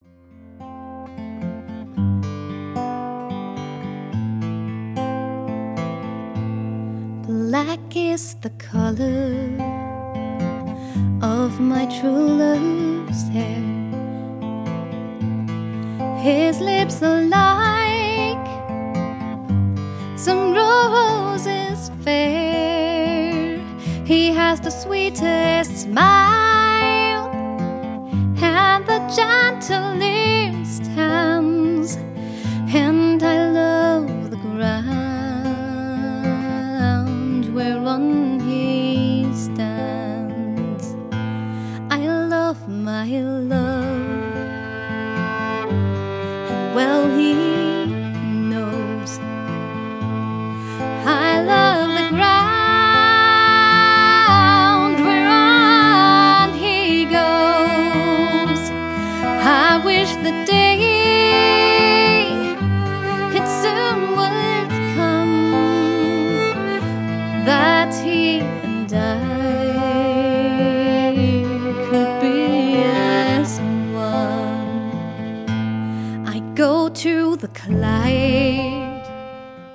Akkordeon